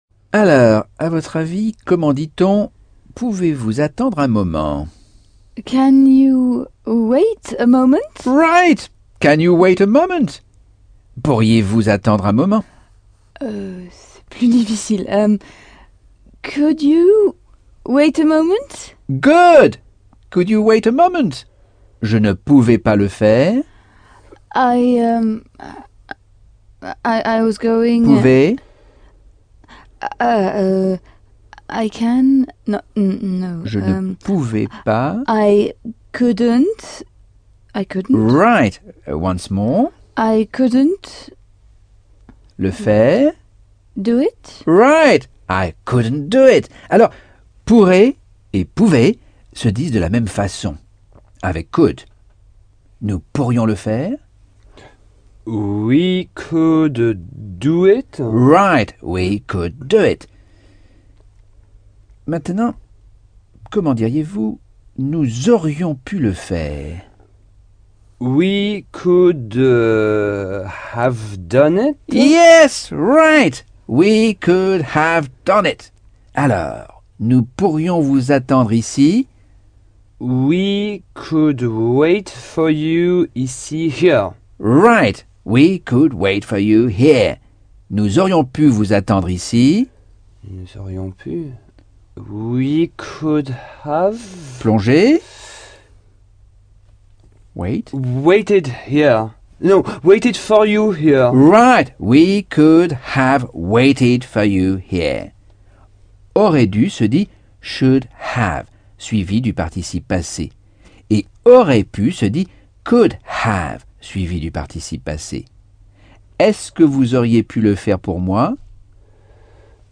Leçon 2 - Cours audio Anglais par Michel Thomas - Chapitre 10